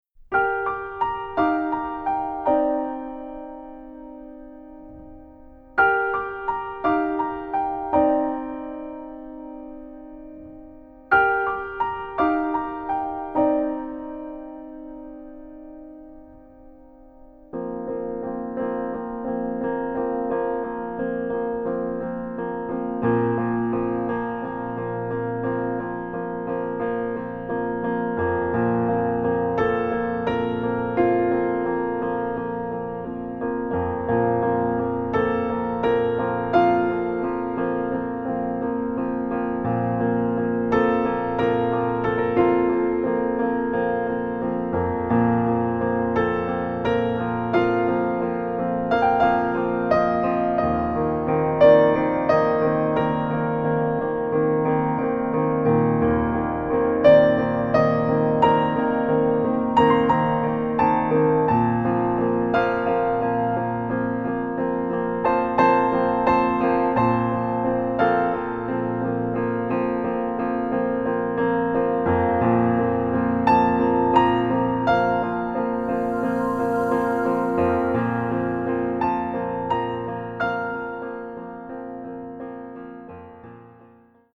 Level : Easy | Key : F | Individual PDF : $3.99